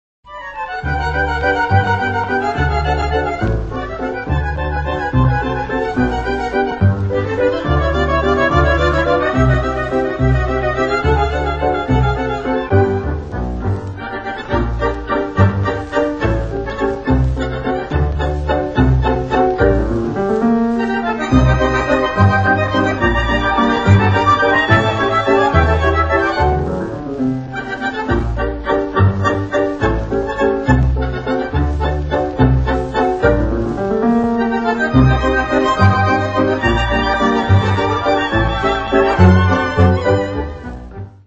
Vals